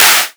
edm-clap-20.wav